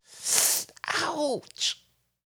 Voice_Ouch_3.wav